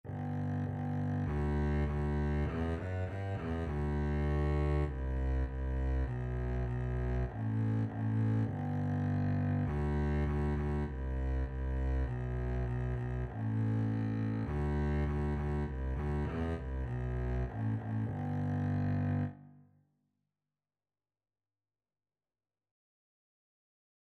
Moderato
G2-G3
G major (Sounding Pitch) (View more G major Music for Double Bass )
Double Bass  (View more Beginners Double Bass Music)
Traditional (View more Traditional Double Bass Music)
Baa_Baa_Black_Sheep_DB.mp3